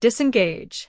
audio_disengage.wav